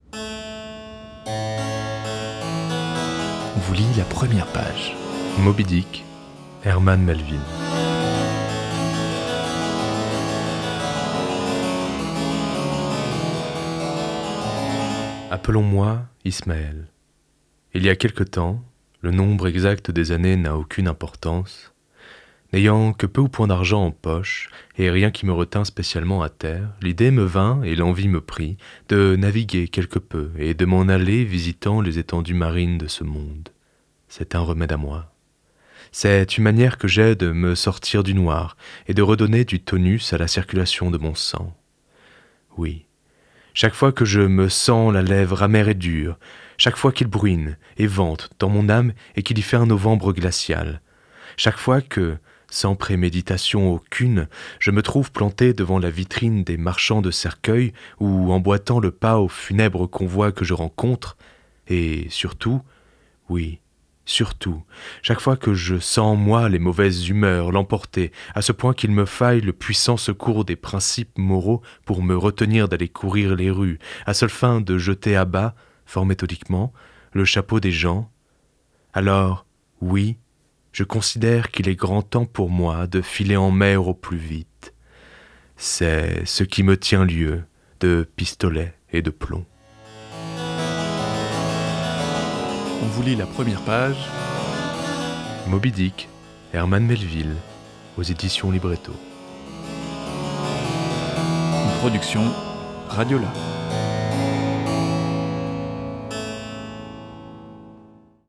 Dans l’émission Première page, RadioLà vous propose la lecture de l’incipit d’un roman.